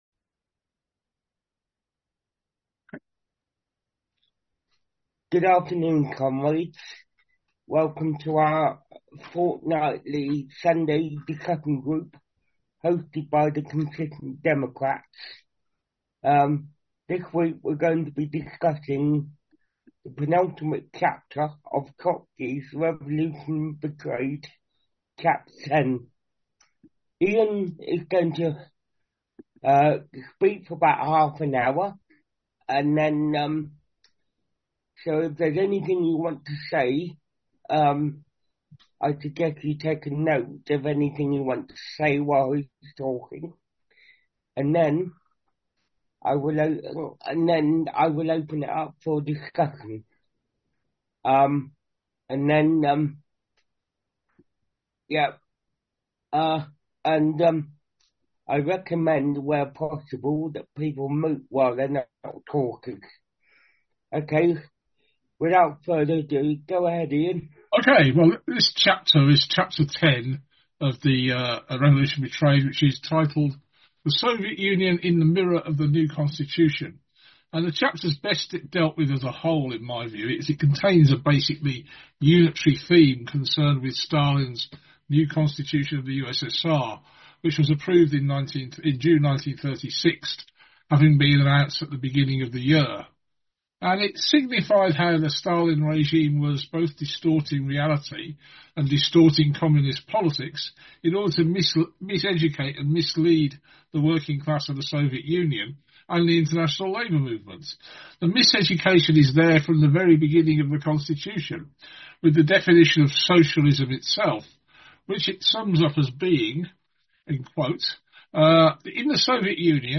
The notes for this presentation (13th April) are now available to read, and the presentation and discussion are also available to listen to as a podcast.